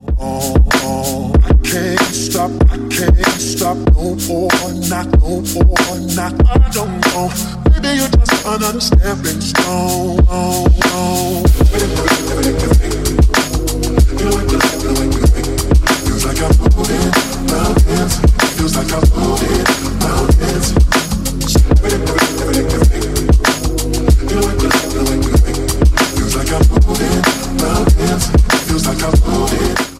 • Качество: 192, Stereo
красивый мужской голос
Electronic
RnB
Hiphop
soul
Chill
beats